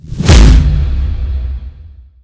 Repulse.ogg